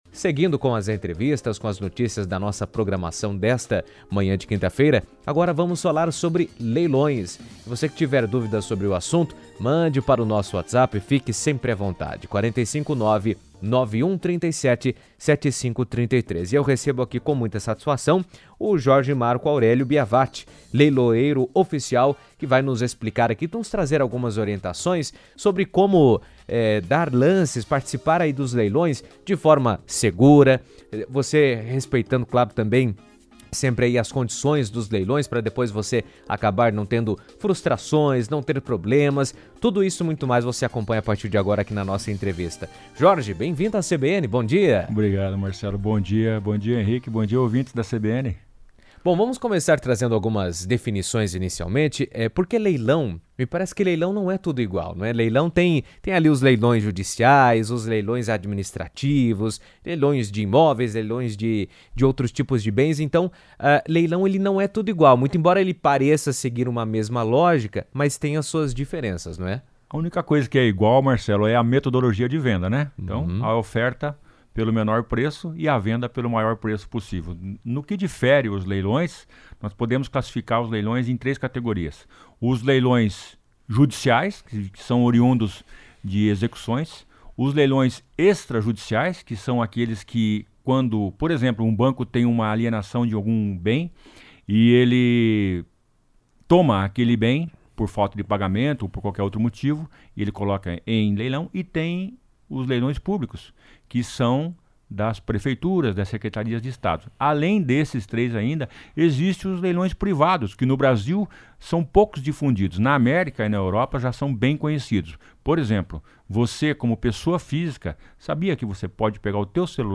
falou sobre o assunto em entrevista à CBN